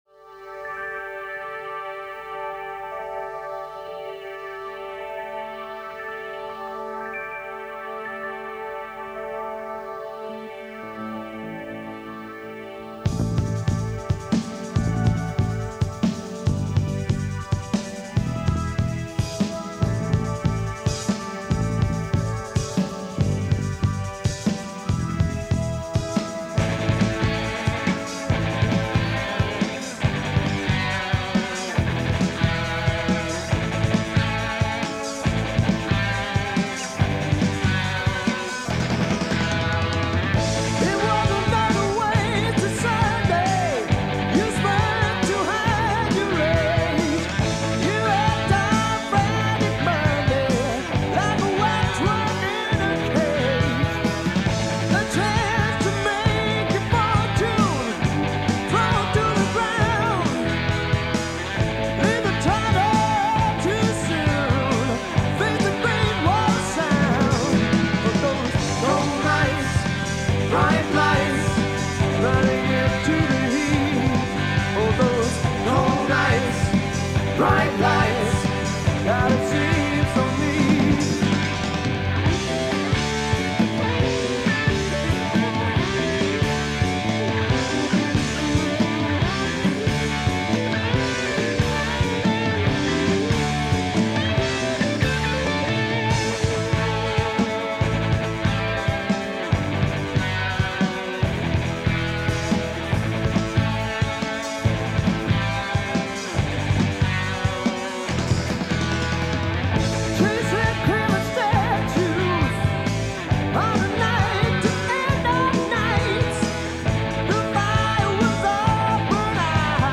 Converting Cassette Album to Digital
Back in 1988 my buddies and I recorded a 12 song cassette of songs we had played together for years.